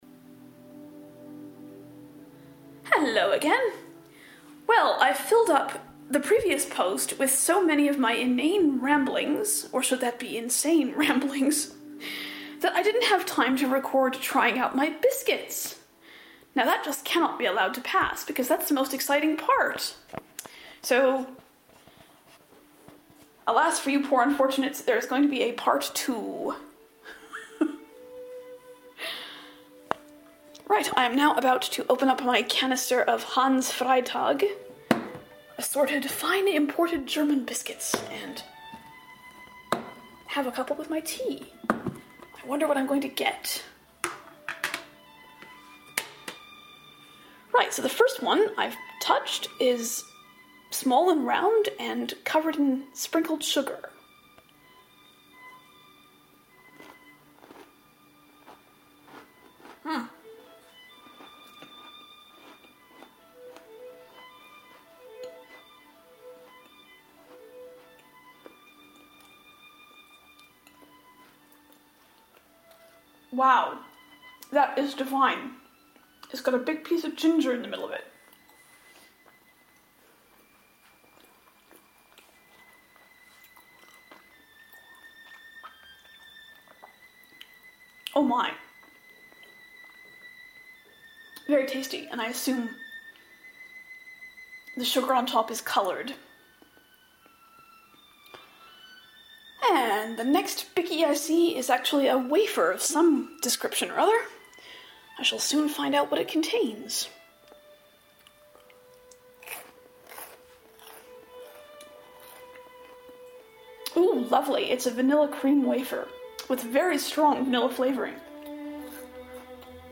Trying my German biscuits to the accompaniment of Vaughan Williams